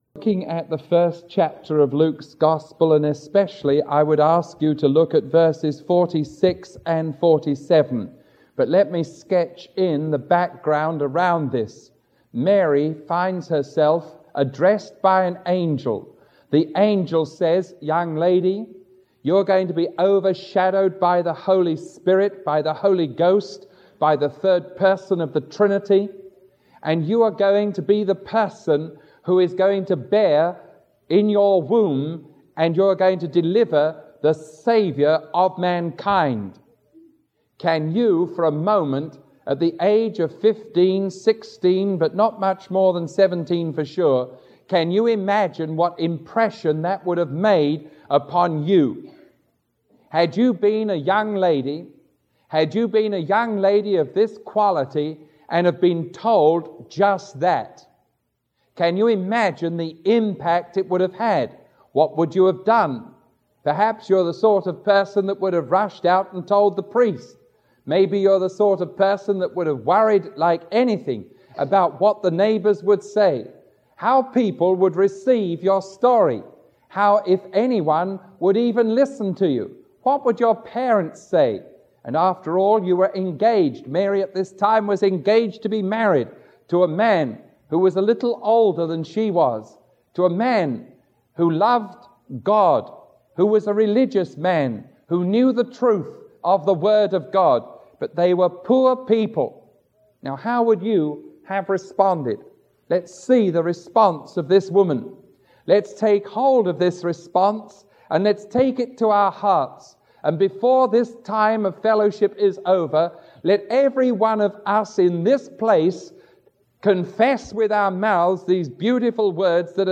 Sermon 0268A recorded on December 14